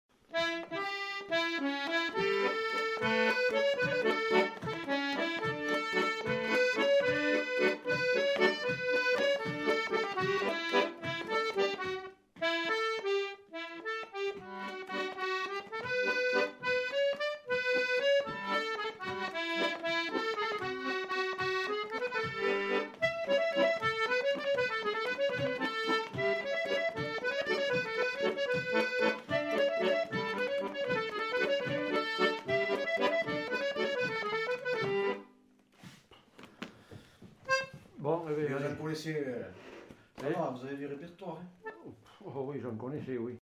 Valse
Aire culturelle : Quercy
Lieu : Bétaille
Genre : morceau instrumental
Instrument de musique : accordéon chromatique
Danse : valse